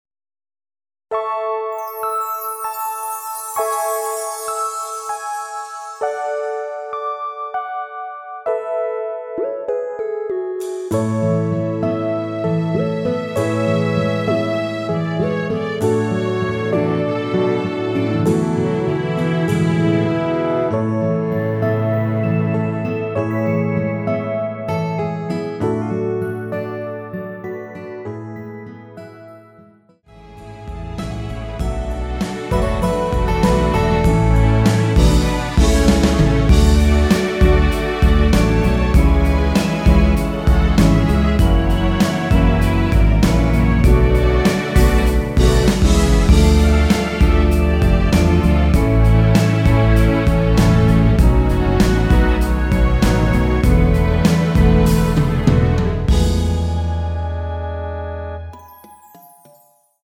원키에서(-4)내린 멜로디 포함된 MR입니다.(미리듣기 참조)
앞부분30초, 뒷부분30초씩 편집해서 올려 드리고 있습니다.
중간에 음이 끈어지고 다시 나오는 이유는